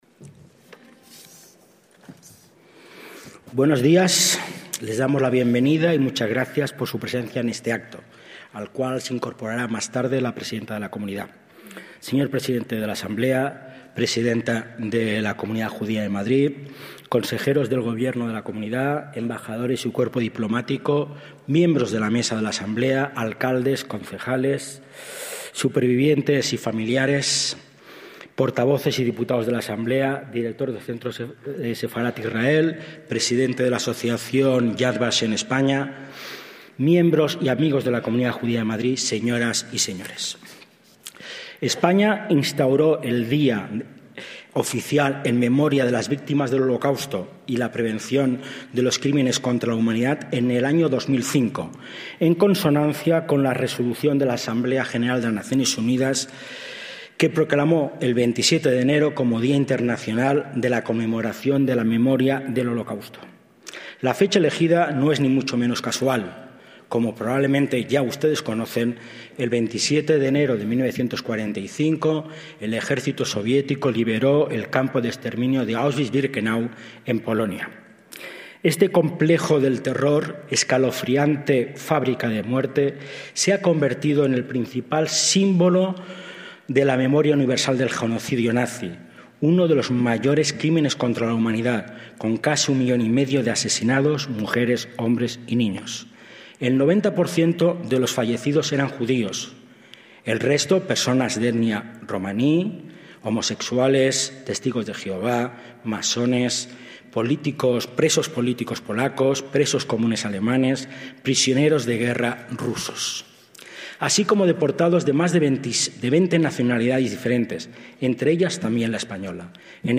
Acto de Recuerdo del Holocausto en la Asamblea de Madrid (30/1/2026)
ACTOS EN DIRECTO - El 30 de enero de 2026 tuvo lugar en la Asamblea de Madrid el Acto en Recuerdo del Holocausto, organizado por la Comunidad Judía de Madrid y la Comunidad Autónoma de la capital.